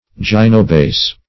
Gynobase \Gyn"o*base\ (j[i^]n"[-o]*b[=a]s), n.